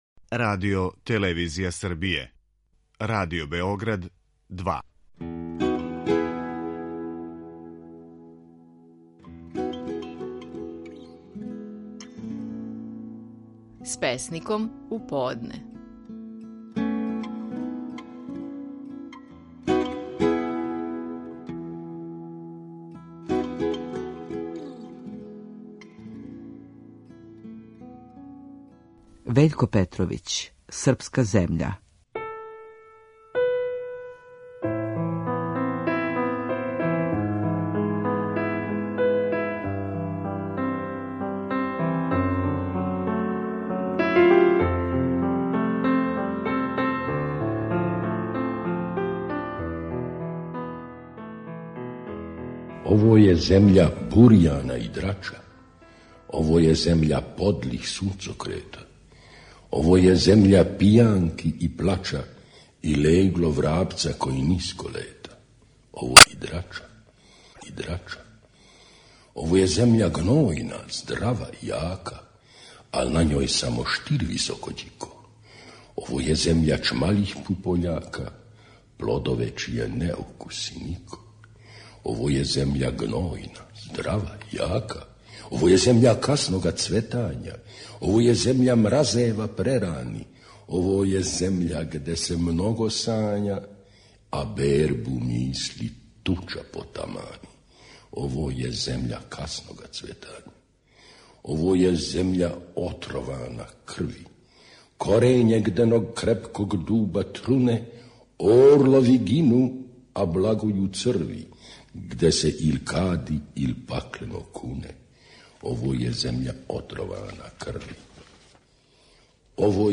Стихови наших најпознатијих песника, у интерпретацији аутора.
Вељко Петровић говори своју песму „Српска земља".